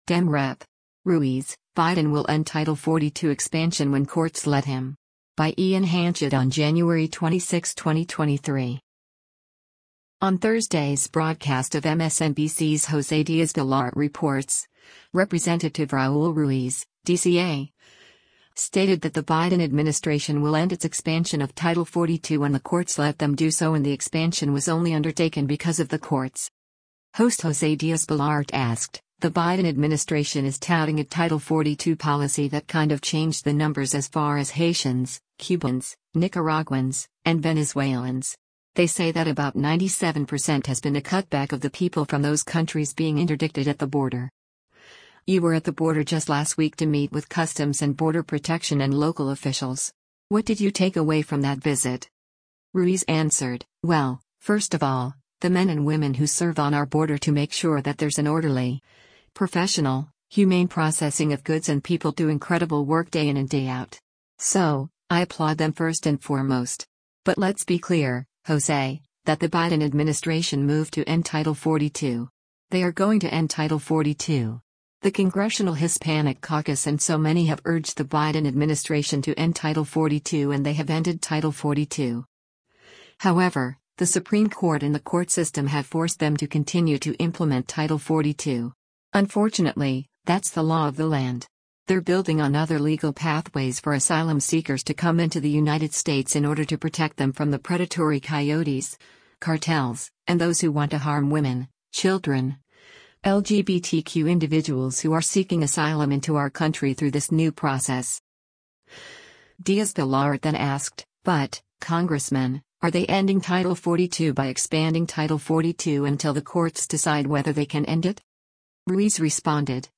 On Thursday’s broadcast of MSNBC’s “Jose Diaz-Balart Reports,” Rep. Raul Ruiz (D-CA) stated that the Biden administration will end its expansion of Title 42 when the courts let them do so and the expansion was only undertaken because of the courts.